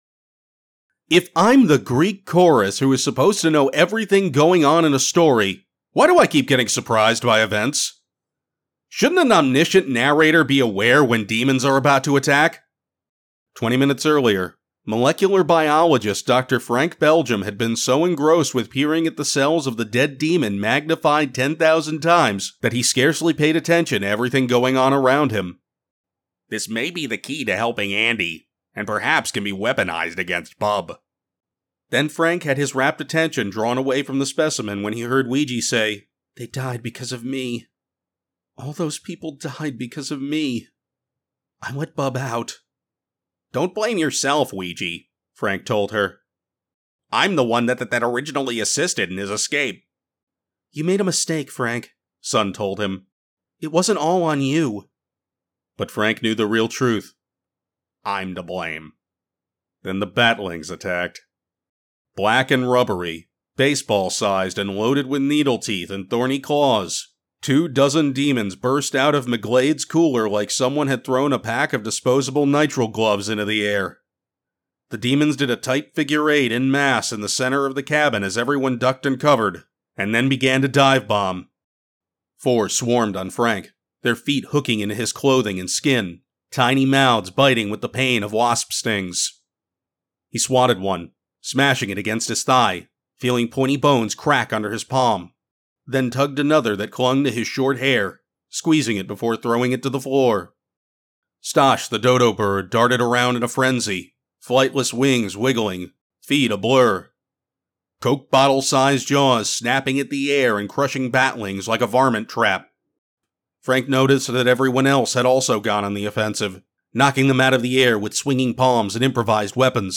When I talk, people listen, and I can lend that gravitas to your story with a rich and clear sound.
Audiobook Sample 2